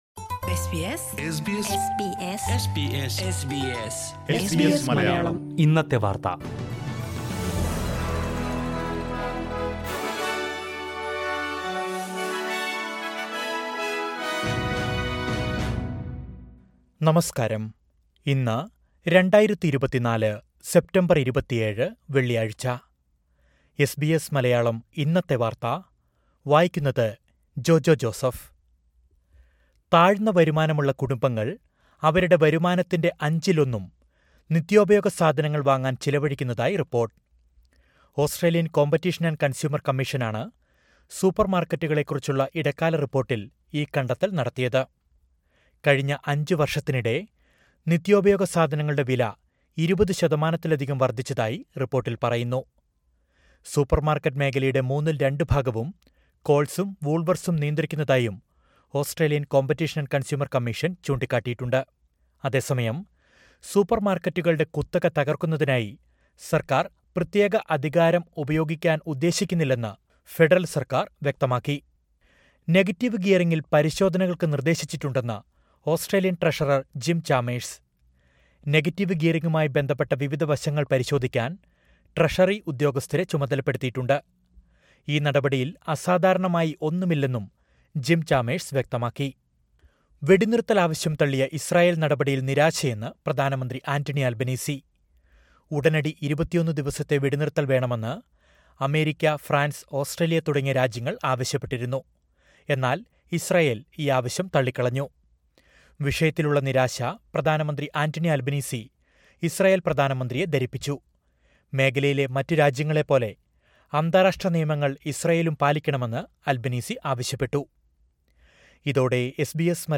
2024 സെപ്റ്റംബര്‍ 27ലെ ഓസ്‌ട്രേലിയയിലെ ഏറ്റവും പ്രധാന വാര്‍ത്തകള്‍ കേള്‍ക്കാം...